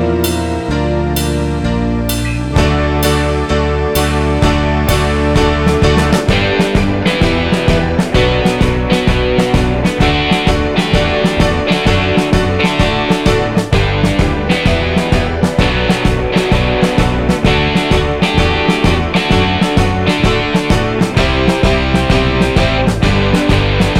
Minus Bass Glam Rock 3:44 Buy £1.50